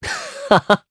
Esker-Vox-Laugh_jp.wav